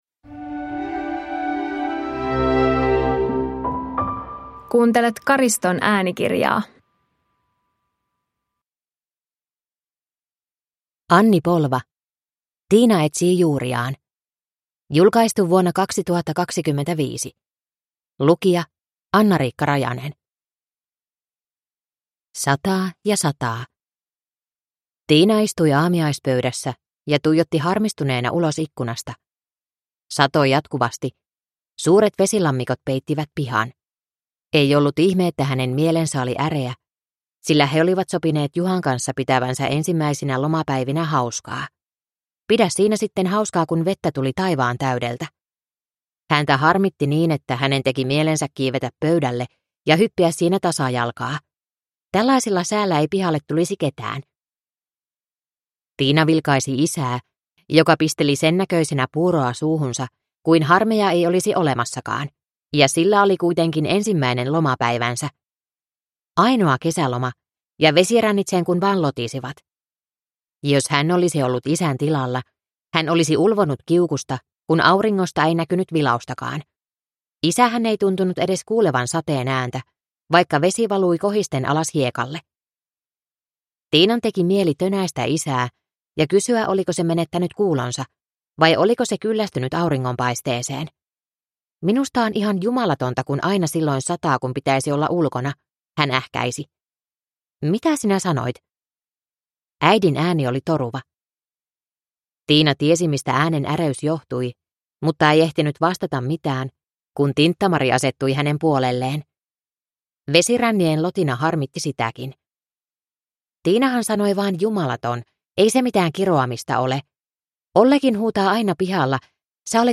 Tiina etsii juuriaan (ljudbok) av Anni Polva